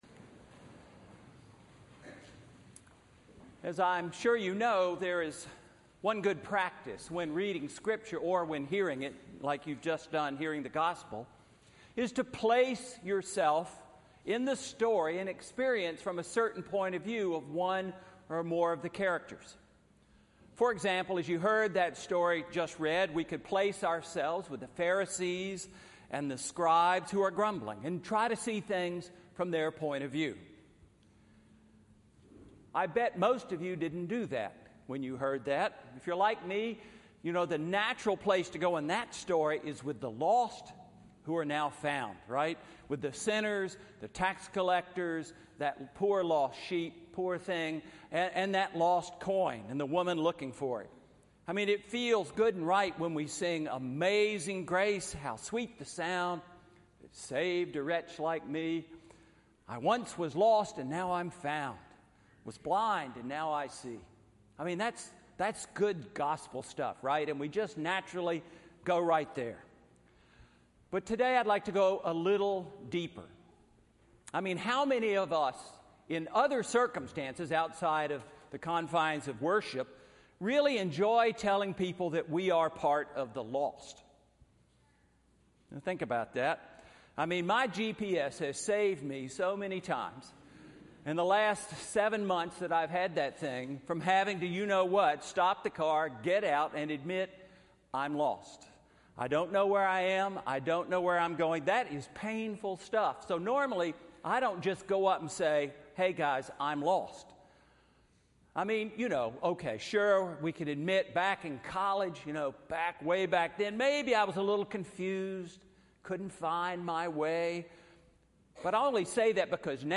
Sermon 2013-09-15 Pentecost 17